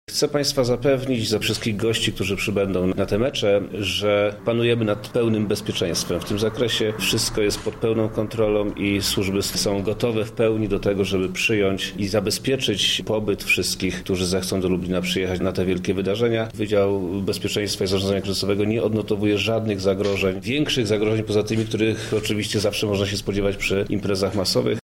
– Zagrożenie przy takich imprezach może wystąpić, ale jest raczej znikome – uspokaja Wojewoda Lubelski Przemysław Czarnek: